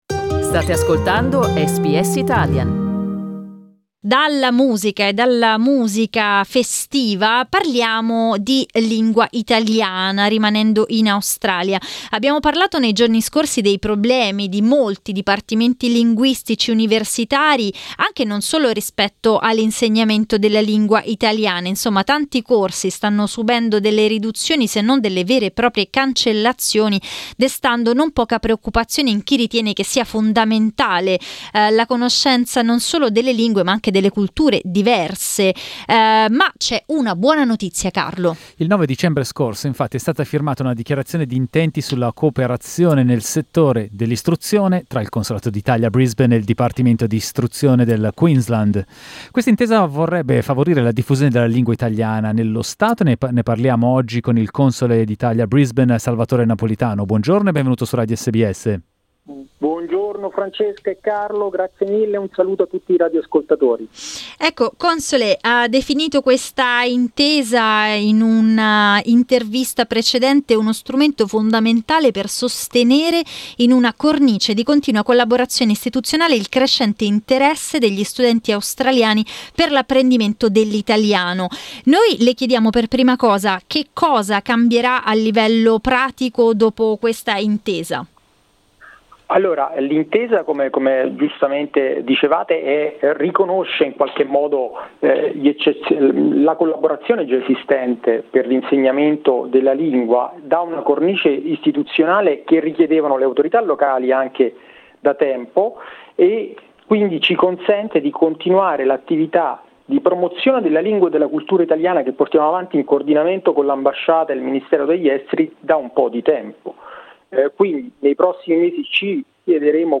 Il console d'Italia a Brisbane Salvatore Napolitano racconta a SBS Italian come la dichiarazione aiuterà nello sviluppo dell'italiano. Ascolta l'intervista.